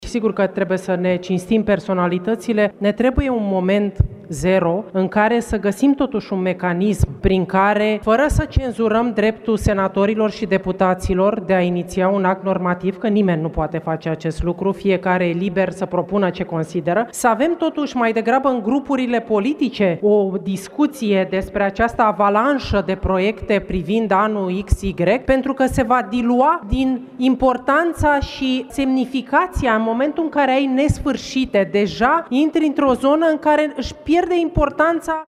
În plenul Senatului a luat cuvântul și senatoarea USR Violeta Alexandru. Aceasta a menționat că se fac prea multe proiecte de acest fel, fără să fie clasificate în funcție de importanță.